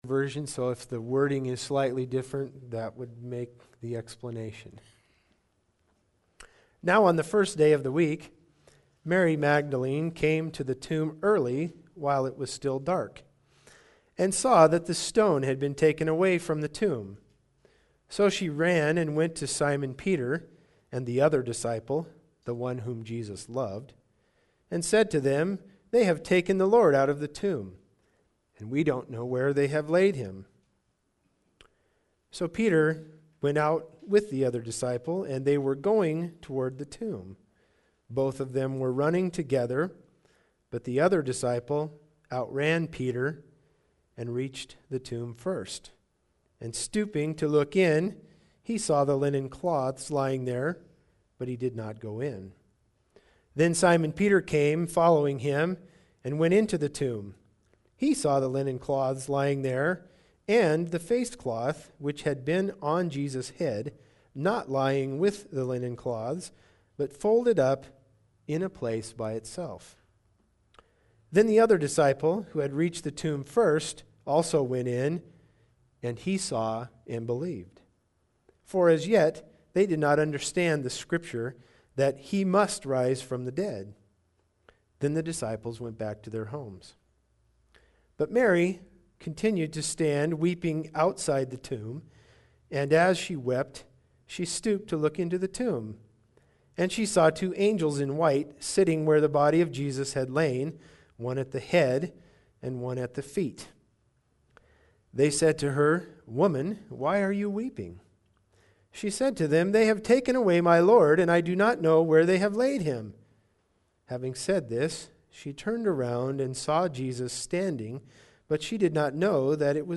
John 20:1-31 Service Type: Sunday Service Bible Text